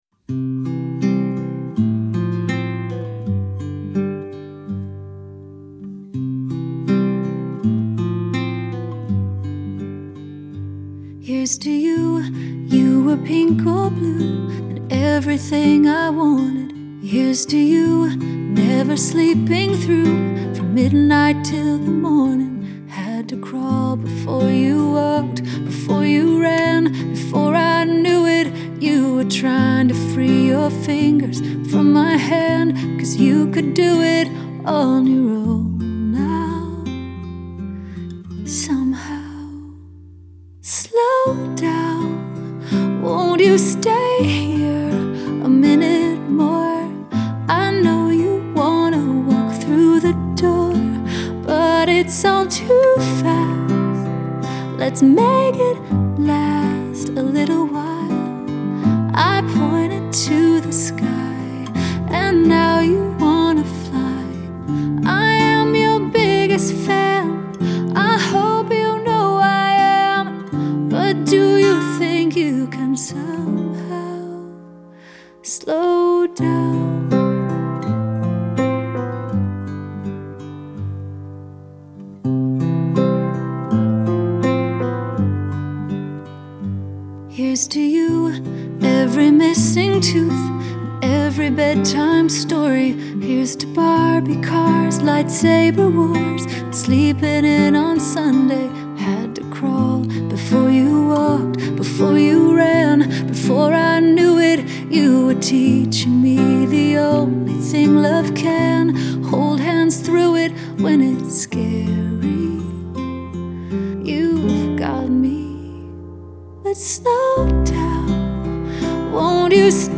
Genre: CCM, Pop, Pop Rock